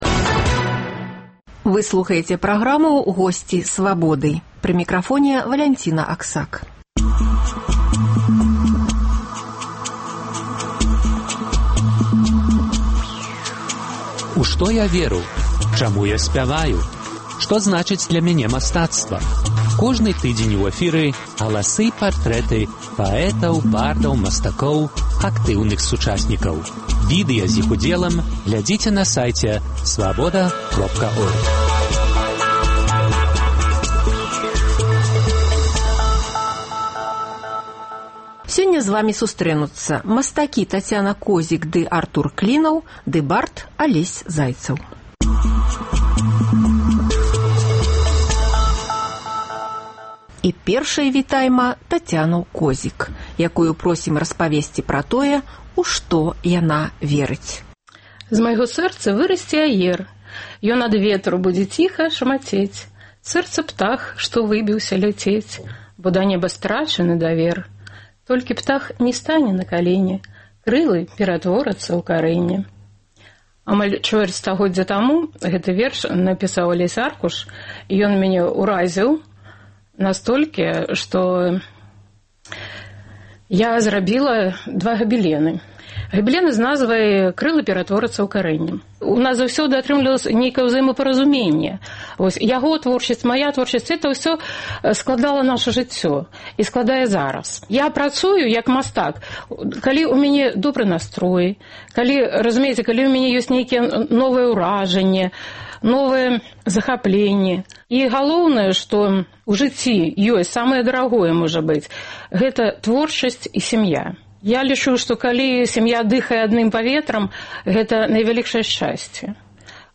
Галасы і партрэты паэтаў, бардаў, мастакоў, актыўных сучасьнікаў.